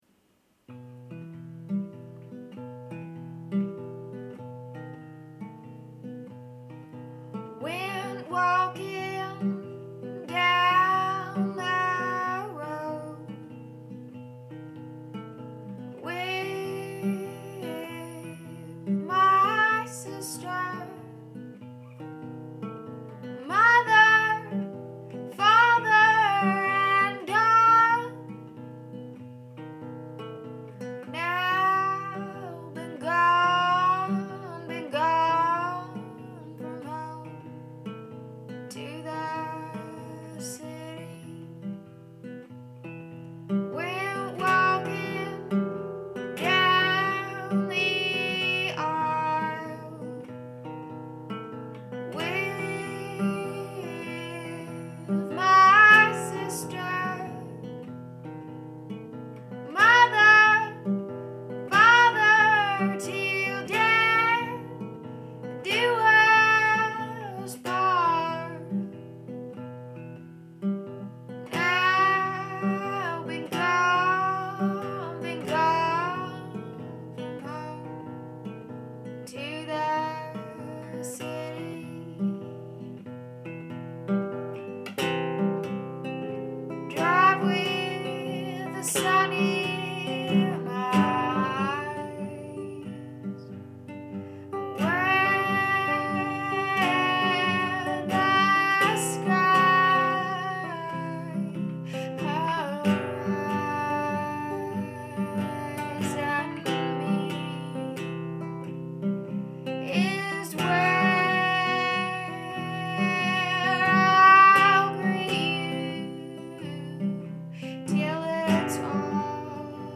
an innocence coupled with troubled thoughts and fears.